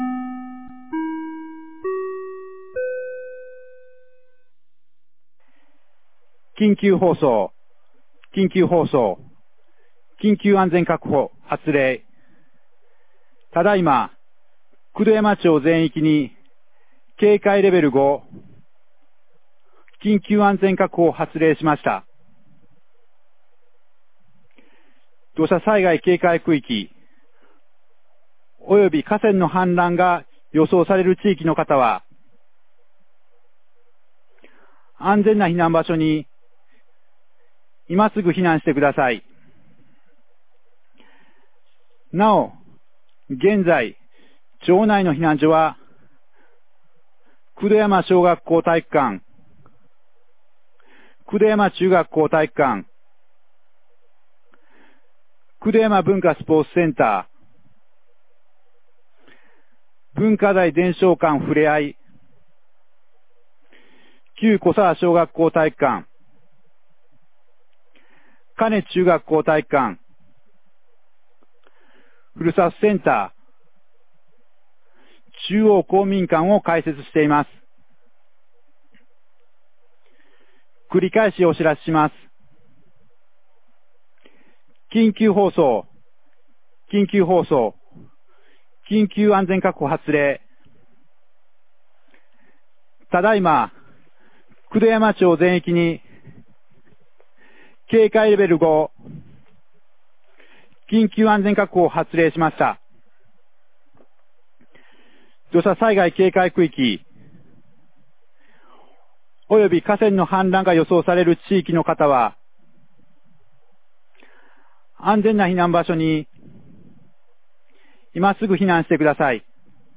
2023年06月02日 14時38分に、九度山町より全地区へ放送がありました。